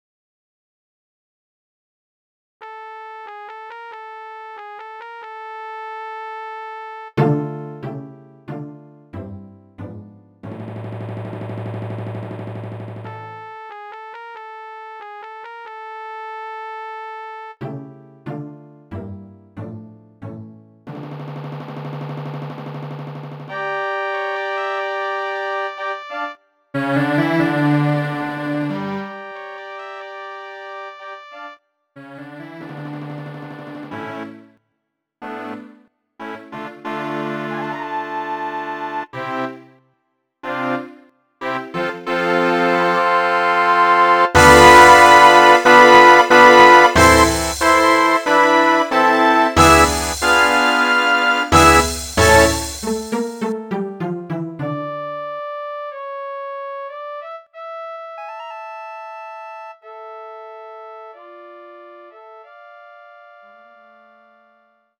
Quelle: MK 5, 233 Musikbeispiel: "László Hunyadi" (computergenerierte WAV-Datei)